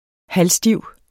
Udtale [ ˈhalˌsdiwˀ ]